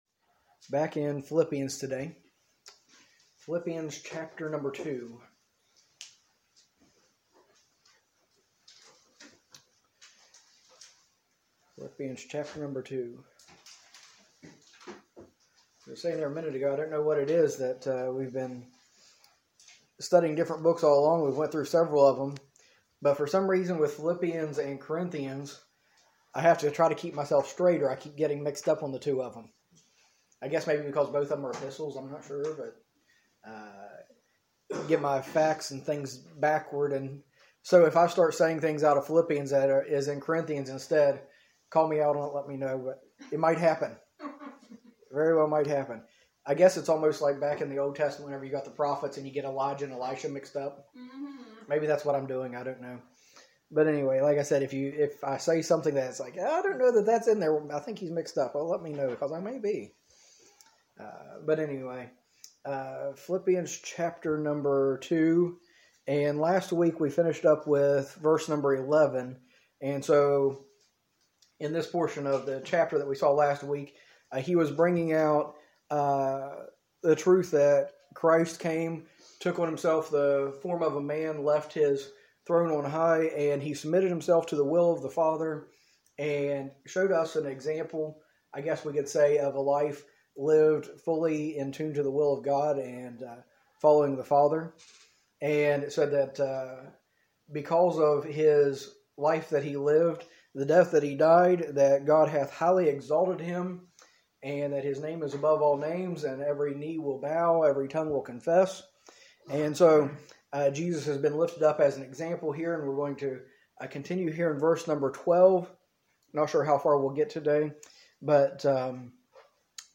A message from the series "Philippians."